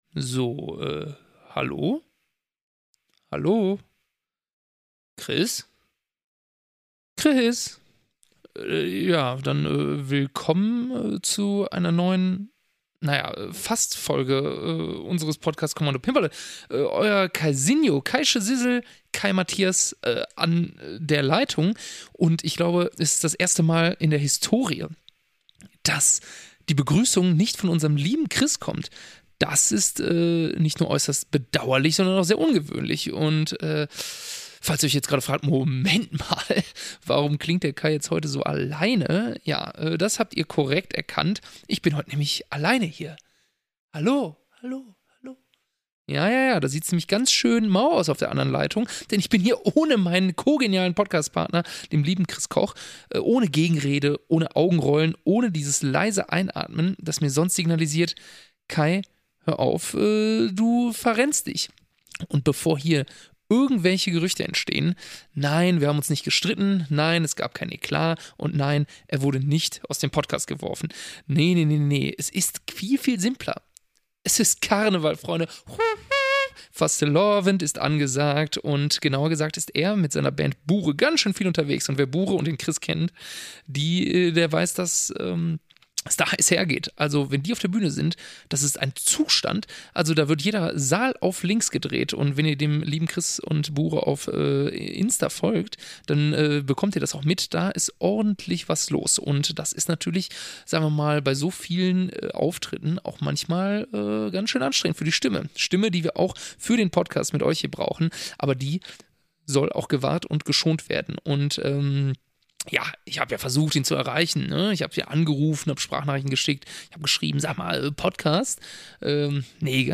aus dem Studio